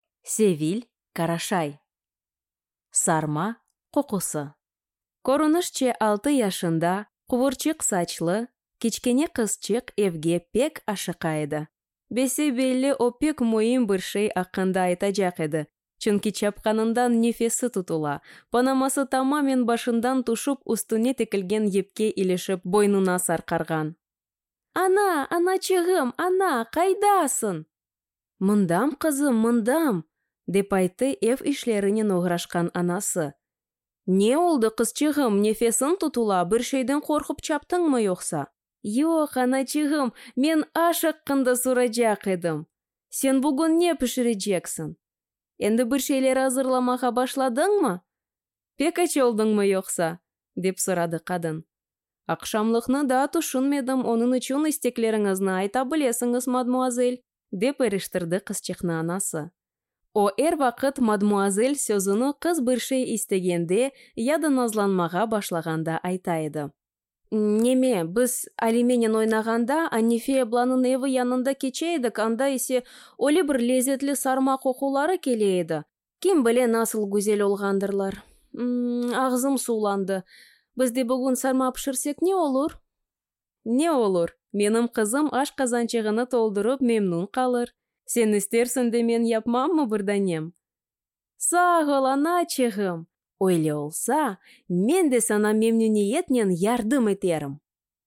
Аудиокнига Сарма къокъусы | Библиотека аудиокниг